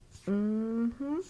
mm-hmm to confirm yes.
mm_hmm.mp3